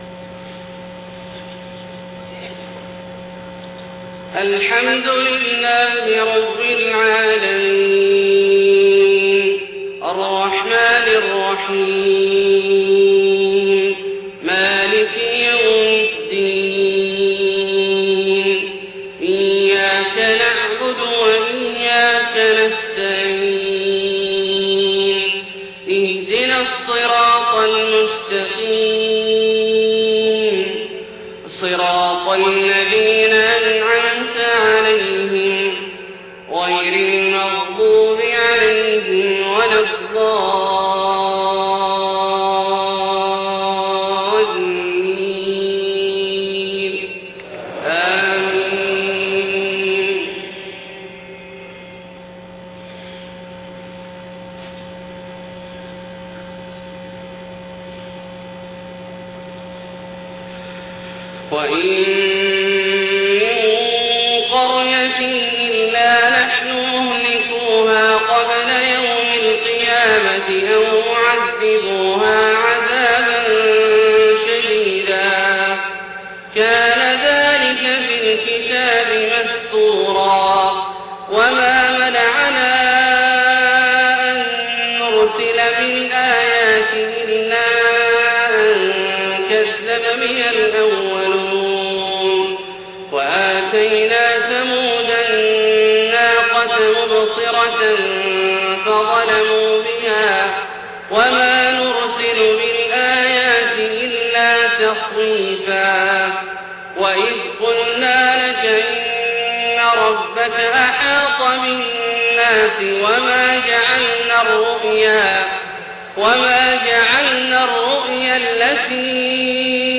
صلاة الفجر 25 محرم 1430هـ من سورة الإسراء 58-84 > 1430 🕋 > الفروض - تلاوات الحرمين